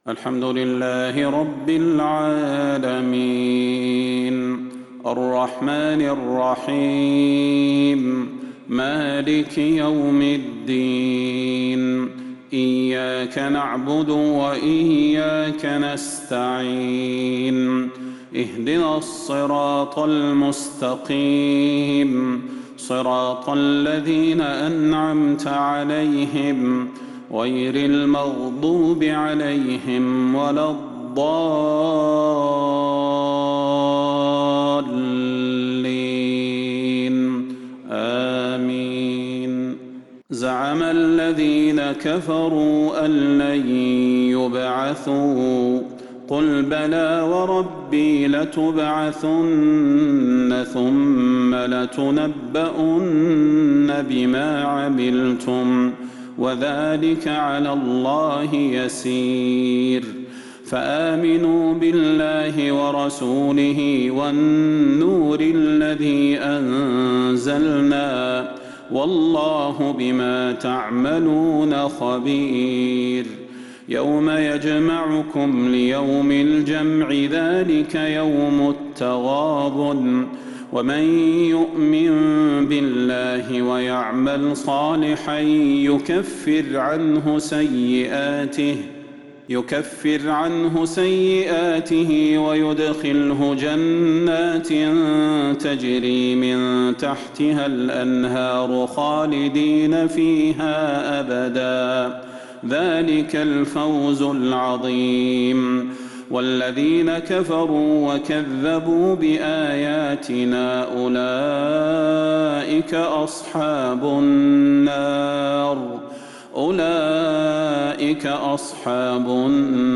صلاة العشاء للقارئ صلاح البدير 16 شوال 1442 هـ